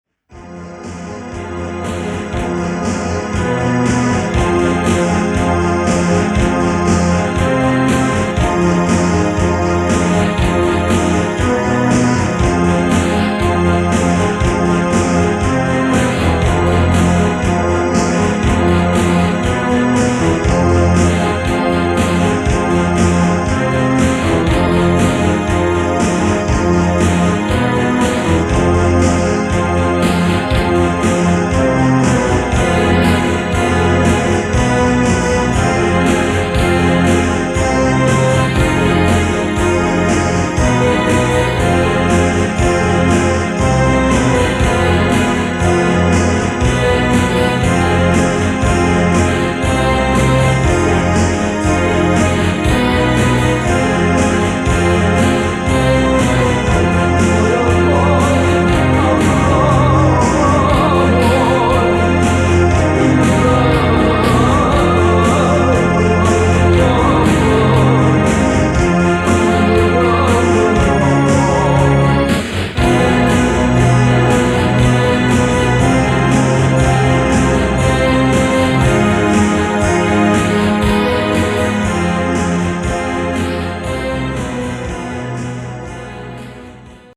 Alto Saxophone
Clarinet
Electric Bass
Keyboards